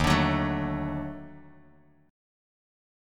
D#mM7b5 chord